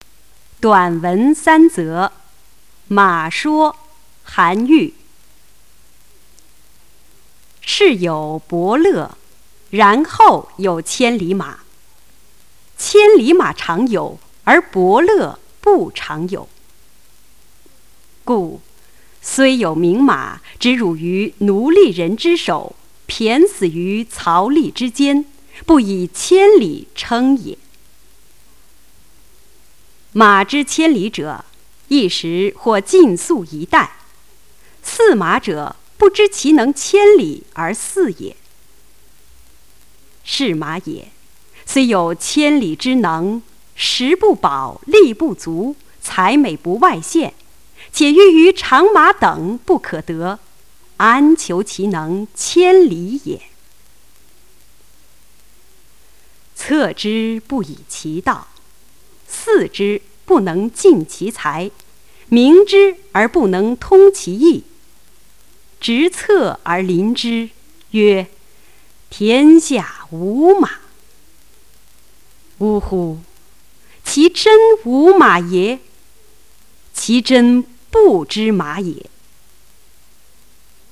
《马说》课文朗读